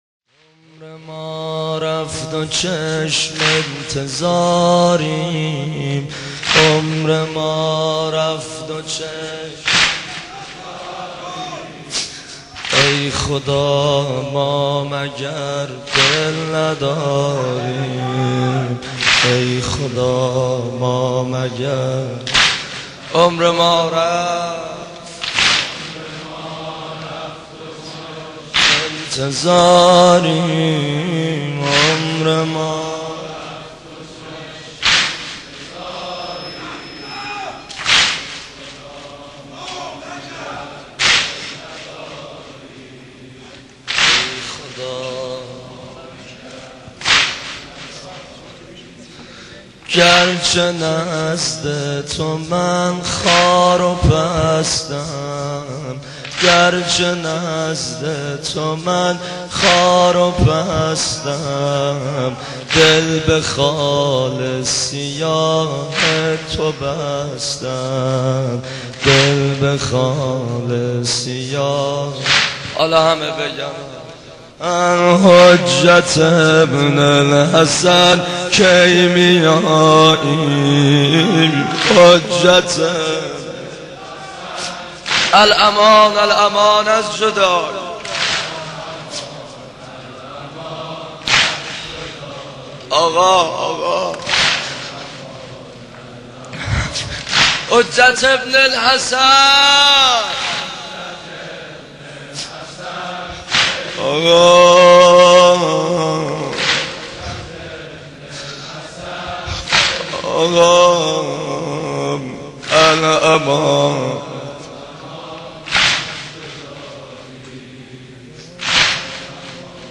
دانلود مداحی